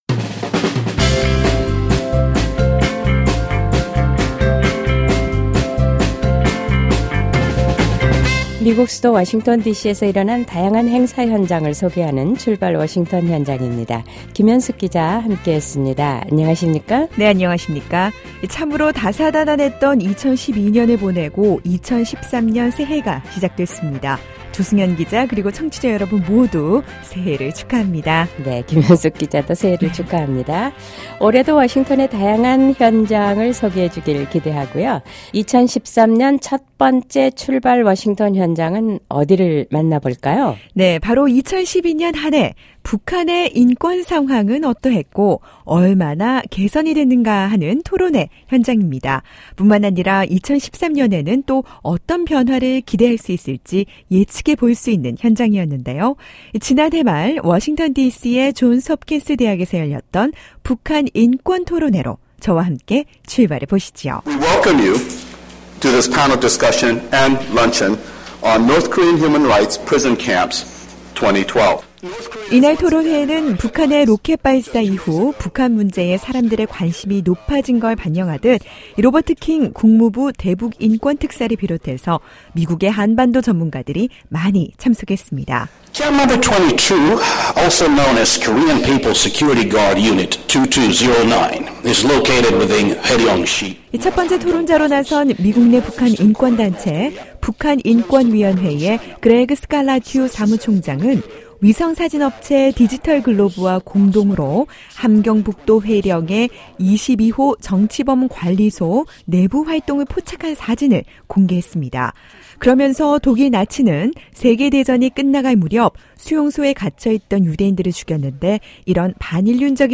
북한이 장거리 미사일을 발사해 세계 언론이 이에 주목하고 있을때에도 워싱턴 디씨에서는 북한 인권에 대해 토의하는 ‘북한 인권 토론회’가 열렸습니다. 김정은 정권에서의 인권 상황을 위성 사진과 수치를 통해 자세히 알아보고 또 2013년 일어날 변화를 전망해 본 토론회 현장을 만나봅니다.